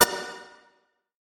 Предупреждение о подтверждении удаления всех файлов из корзины